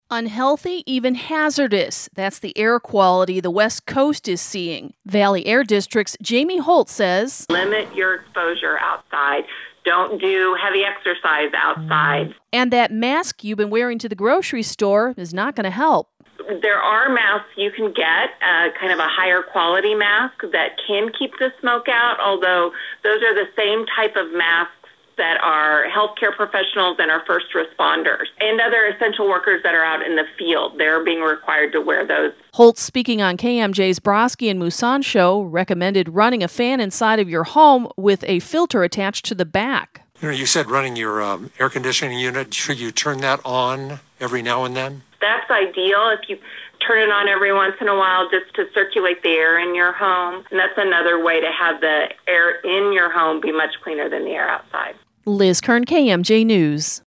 LK-BAD-CREEK-FIRE-AIR.mp3